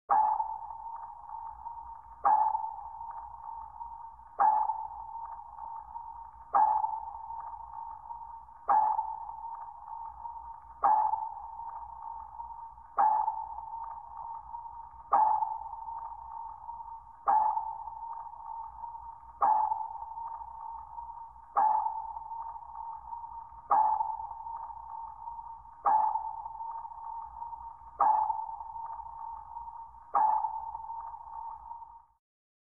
Звуки сонара
Звуки сонара создают таинственную атмосферу, напоминающую о глубинах океана и морских исследованиях.
Одиночный звук с писком эхолота